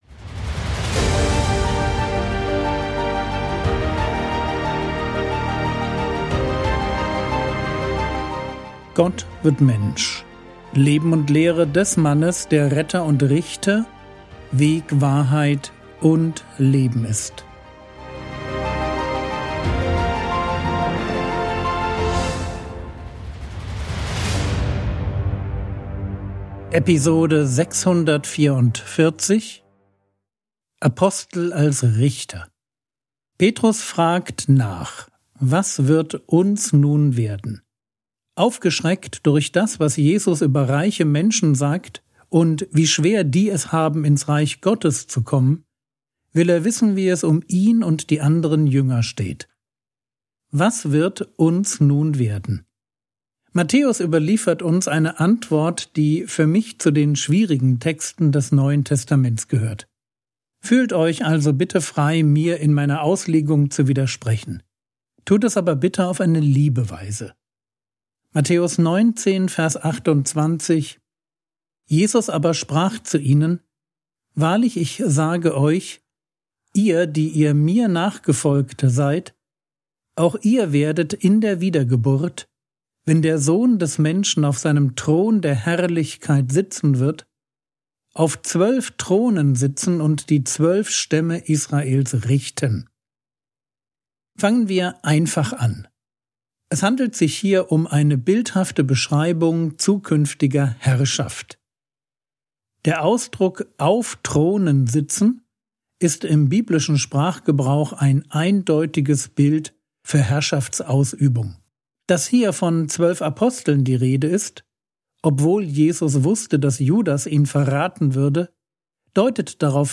Episode 644 | Jesu Leben und Lehre ~ Frogwords Mini-Predigt Podcast